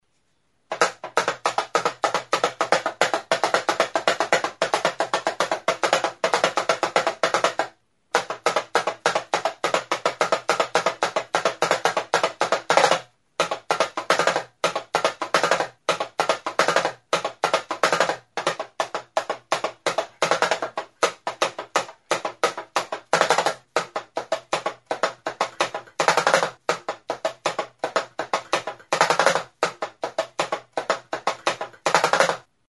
Enregistré avec cet instrument de musique.
DAMARU; Hourglass drum; Tambor reloj de arena
Membranophones -> Frappés -> Indirectement
Sokarekin tinkaturik dago, eta soka honen luzapenaren muturrean bolatxo gisako korapiloa du larruak kolpeatzeko danborra astintzerakoan.